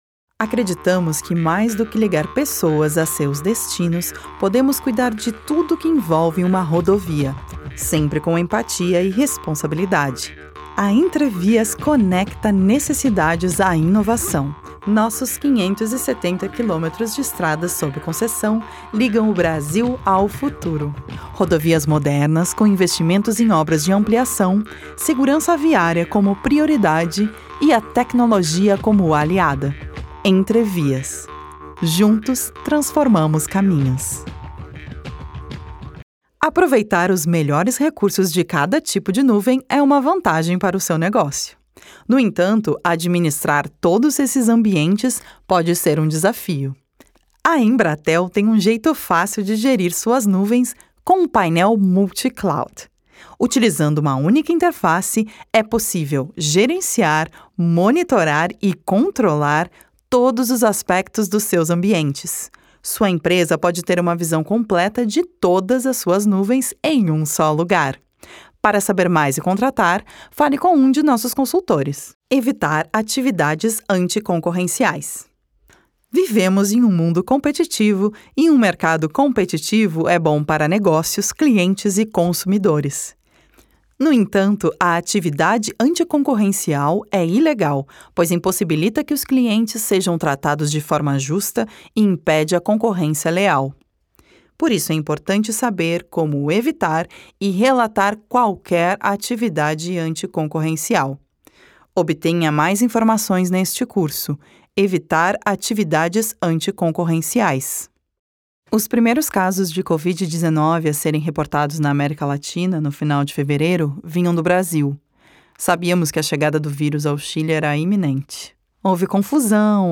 Feminino
Voice reel Corporativo Português
Voz Padrão - Grave 02:24